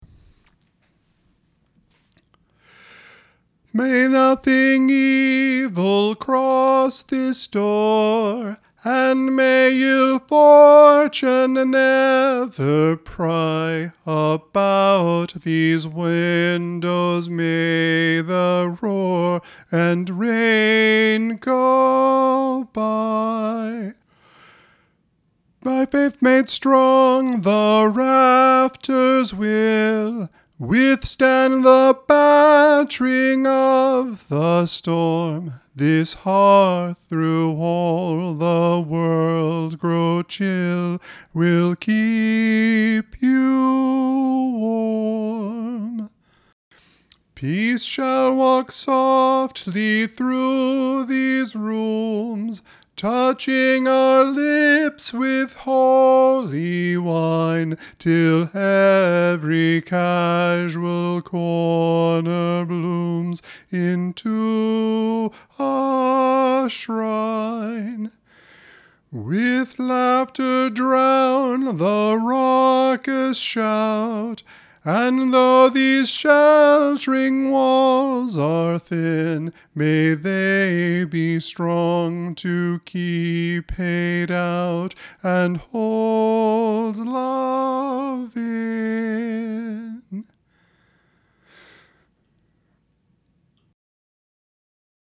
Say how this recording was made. not perfectly in tune, but might help tenors or basses learn the music by